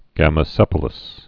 (gămə-sĕpə-ləs)